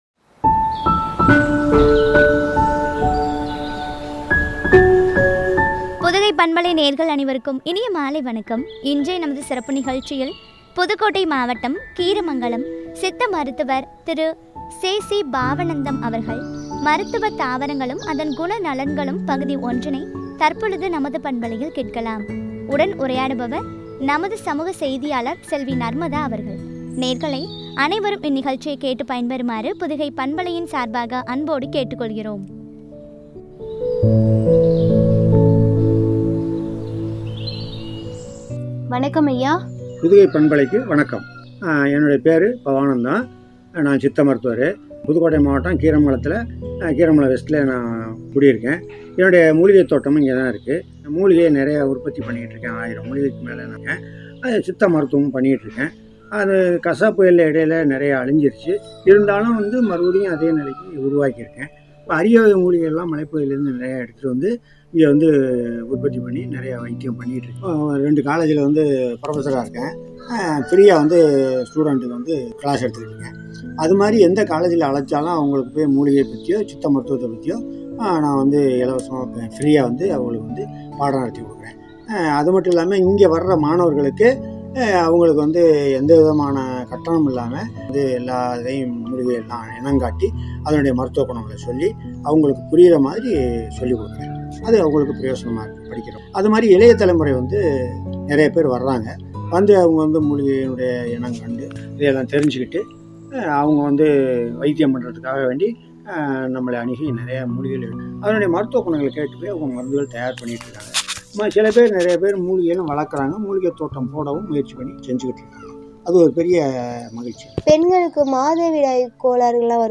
என்ற தலைப்பில் வழங்கும் உரையாடல்.